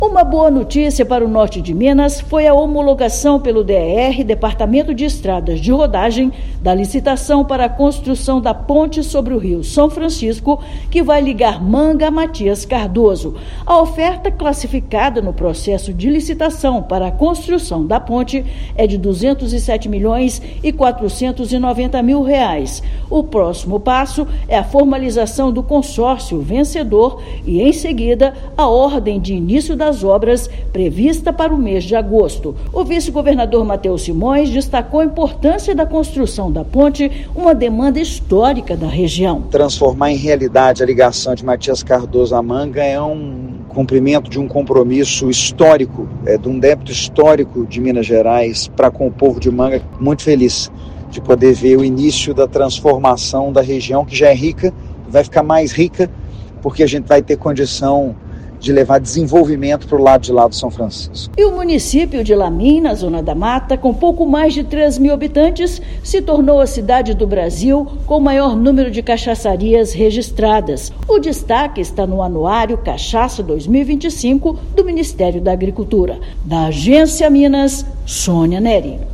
A oferta classificada, no processo de licitação, para a construção da ponte, é de R$ 207,4 milhões. Ouça matéria de rádio.